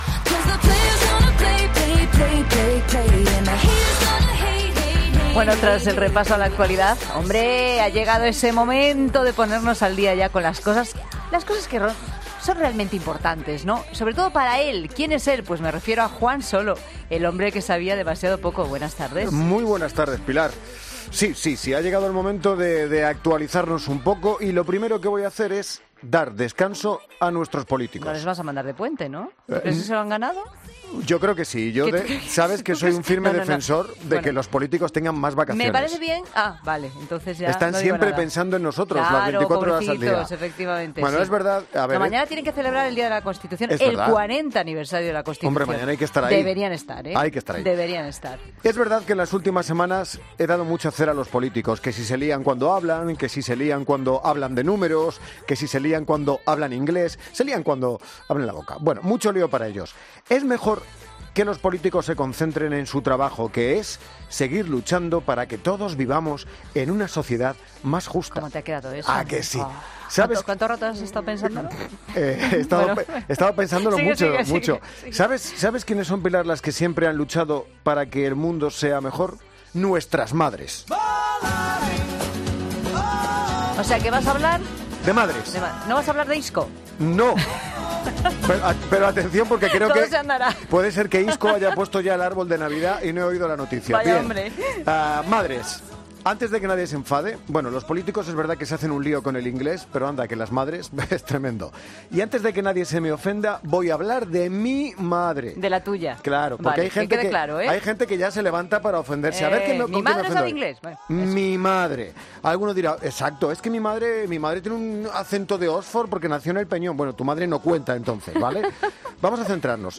Humor: 'Los presidentes de fútbol hablan raro'